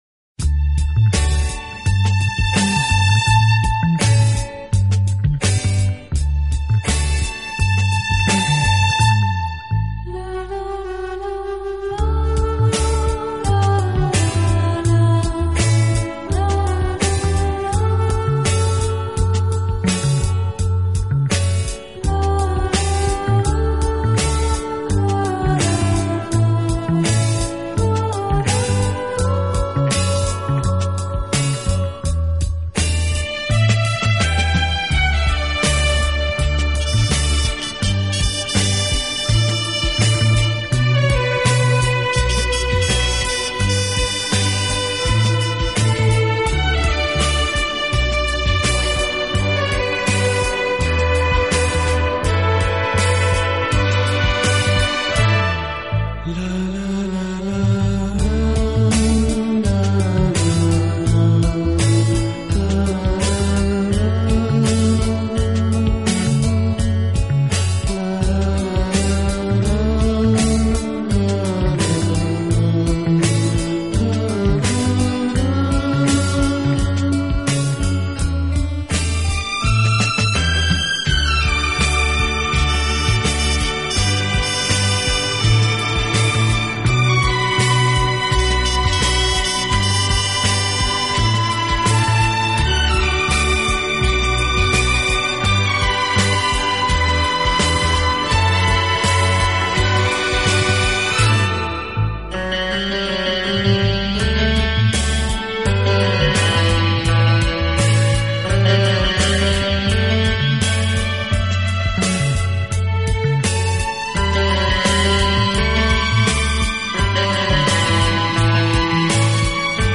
【轻音乐】
“清新华丽，浪漫迷人”